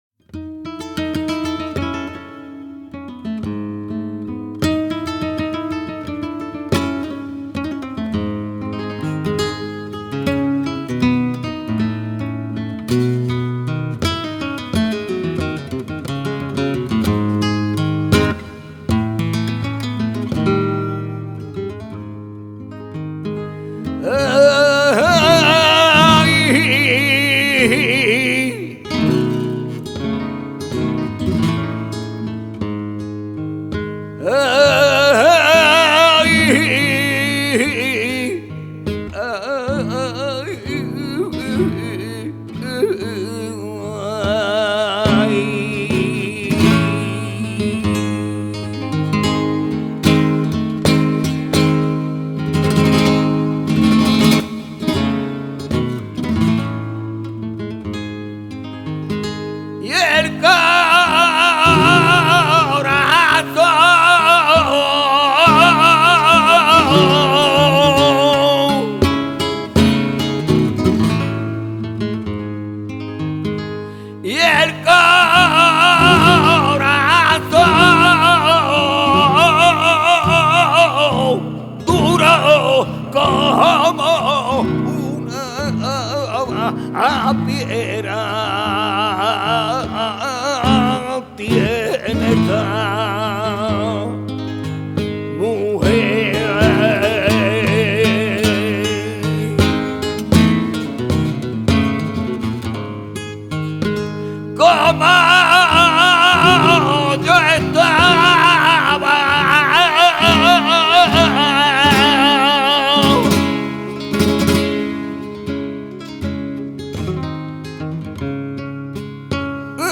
guitare
siguiriyas